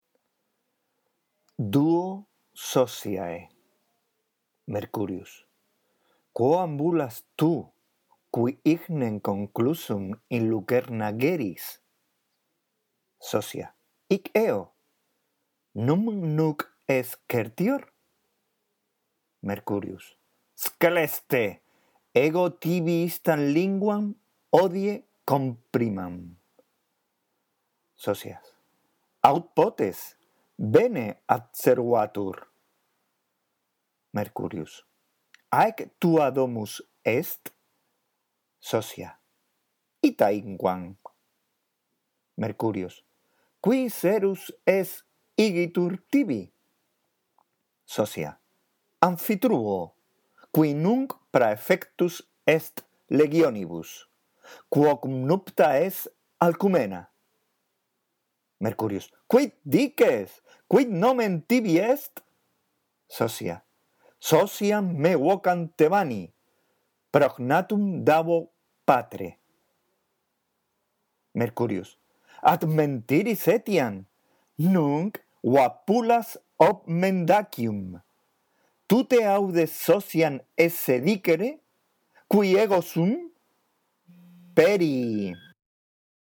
La audición de este archivo te ayudará en la práctica de la lectura del latín: